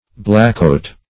Search Result for " blackcoat" : The Collaborative International Dictionary of English v.0.48: Blackcoat \Black"coat`\, n. A clergyman; -- familiarly so called, as a soldier is sometimes called a redcoat or a bluecoat.